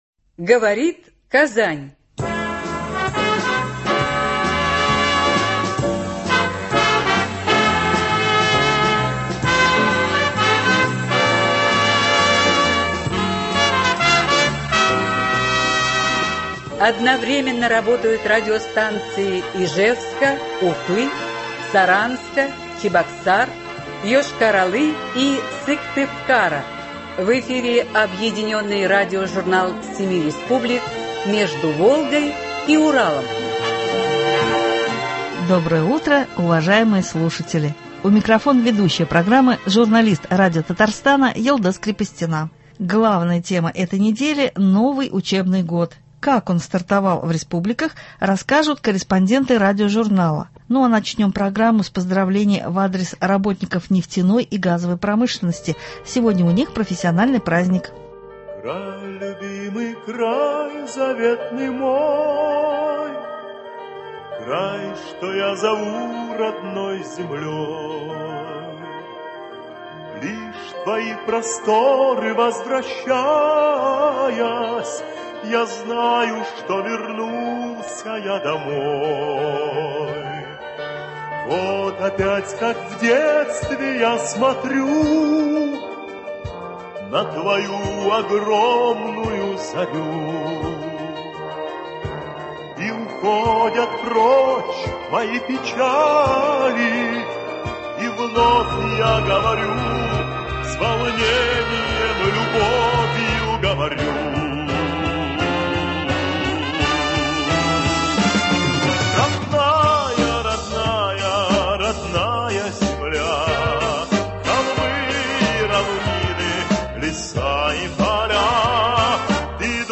Объединенный радиожурнал семи республик.
Как он стартовал в республиках – расскажут корреспонденты радиожурнала.